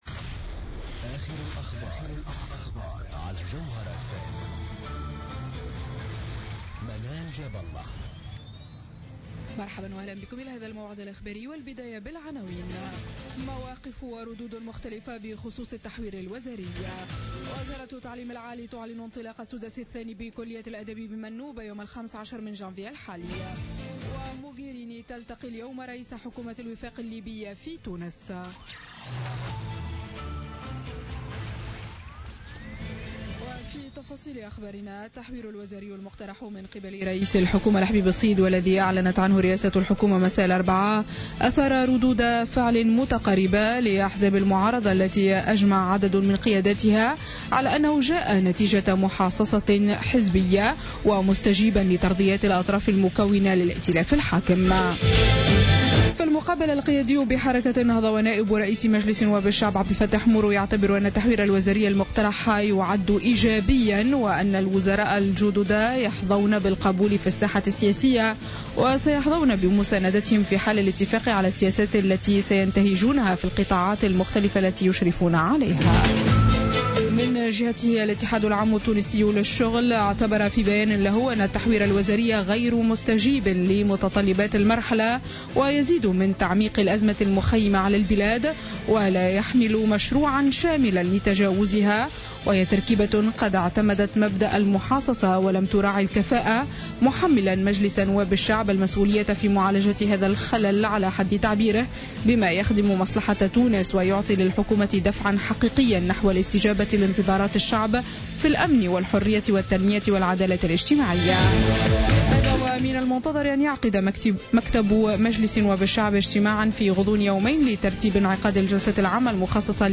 نشرة أخبار منتصف الليل ليوم الجمعة 8 جانفي 2015